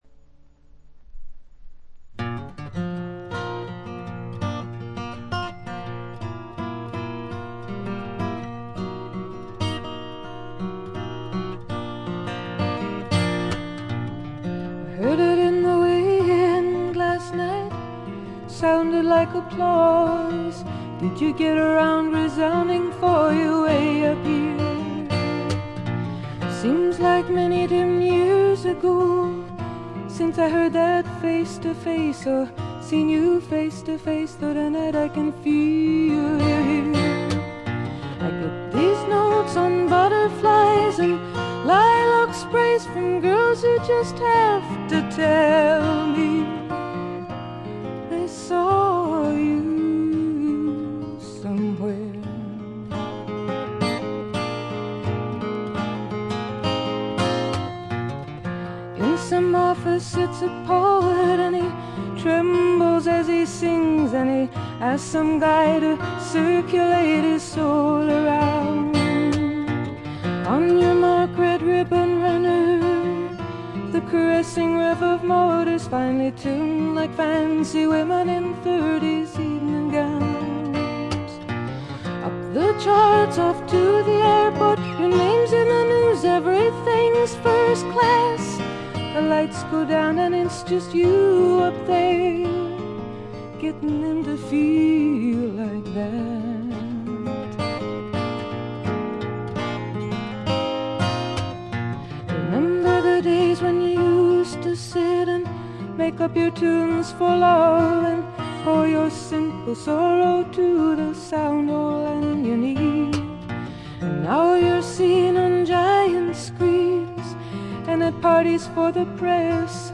わずかなノイズ感のみ。
試聴曲は現品からの取り込み音源です。
Recorded At - A&M Studios